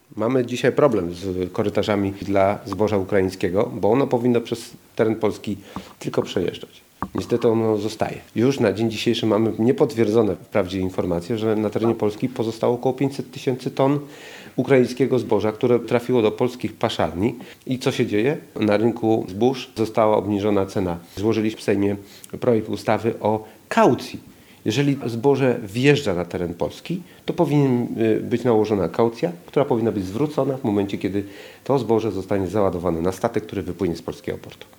Uwolnienie energii z OZE, dopłata do węgla, oleju opałowego, pelletu, ekogroszku czy też likwidacja podatku Belki – to tylko niektóre z propozycji Polskiego Stronnictwa Ludowego przedstawione przez posła Jarosława Rzepę podczas dzisiejszej konferencji prasowej w Szczecinie.